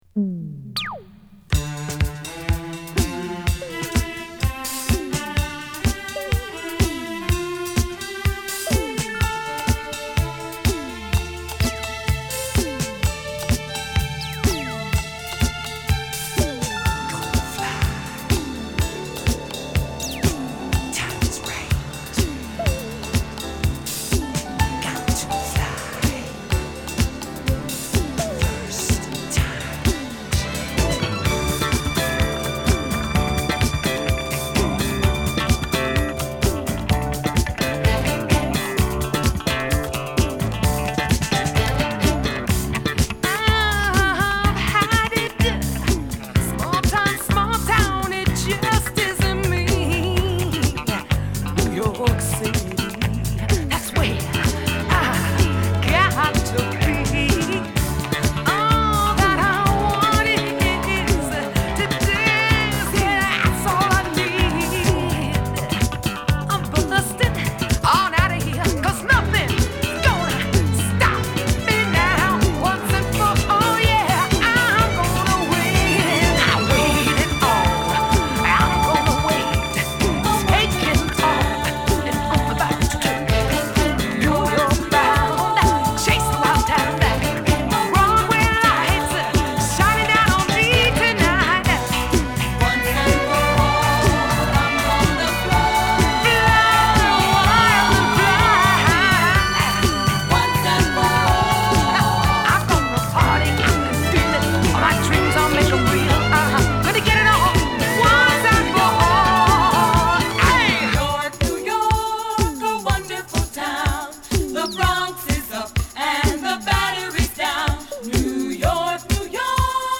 ディスコブレイクも飛び出すナイストラックにポップなヴォーカルが乗るグッド・ディスコチューン！！